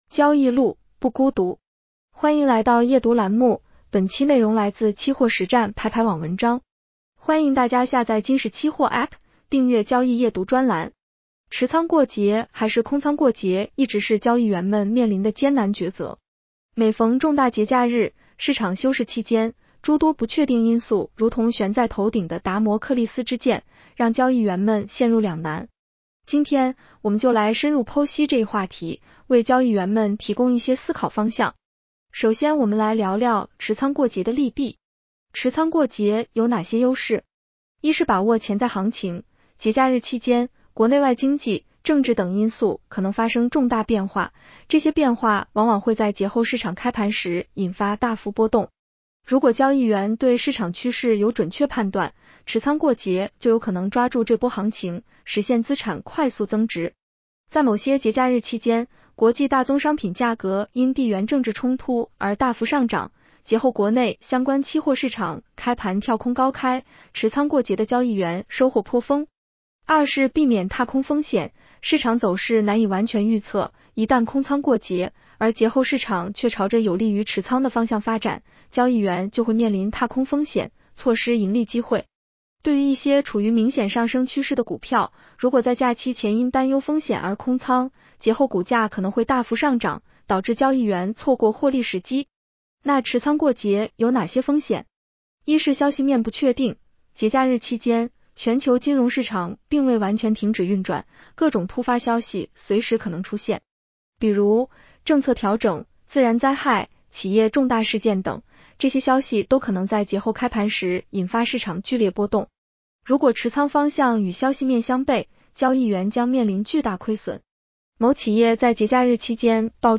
【期货交易夜读音频版】
女声普通话版 下载mp3